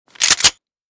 mossberg_unjam.ogg.bak